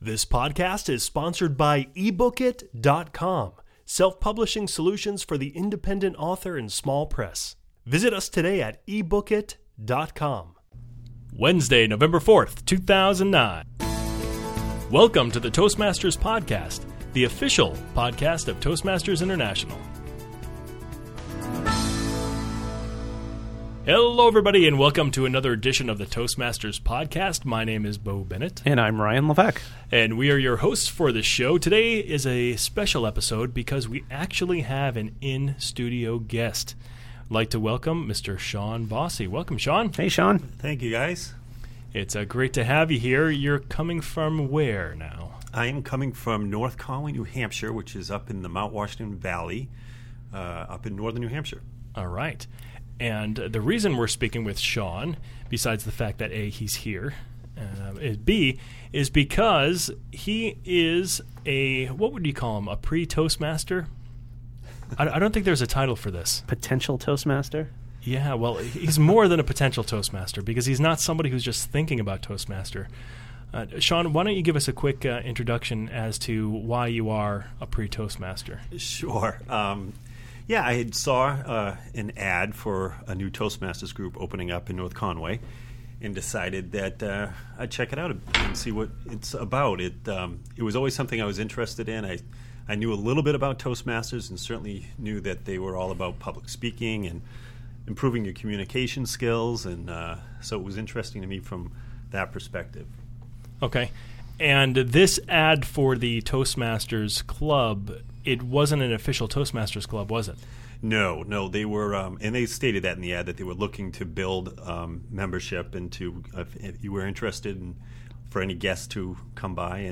Podcast Episode Toastmasters Podcast #015: Interview with a "Pre Toastmaster" 2009-11-06 Listen to this episode